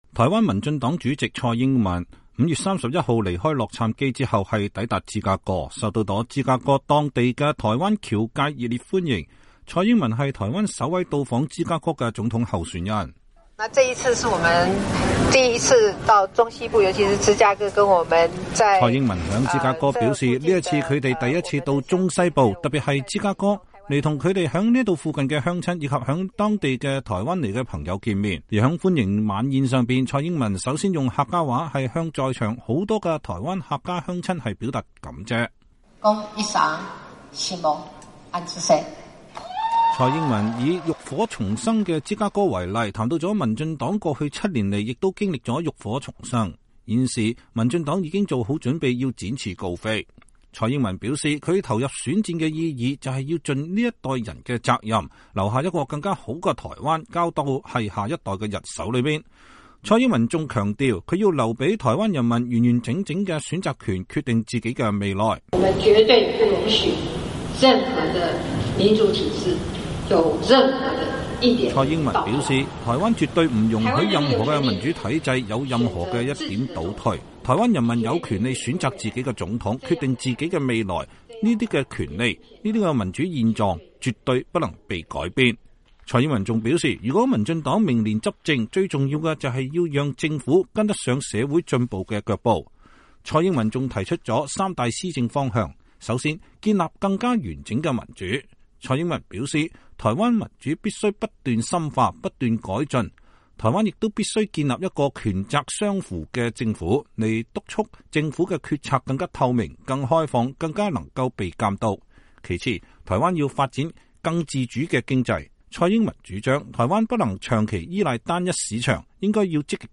台灣民進黨主席暨總統參選人蔡英文在芝加哥接受媒體訪問。（視頻截圖）
在歡迎晚宴上，蔡英文首先用客家話向在場許多台灣客家鄉親表達感謝。